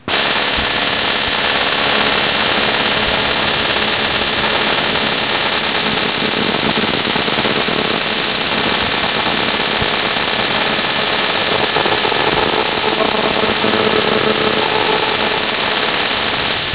The Amazing All-Band Receiver is basically a diode detector followed by a high-gain audio amplifier.
sound of a U.K. cell phone tower!
Cell tower.wav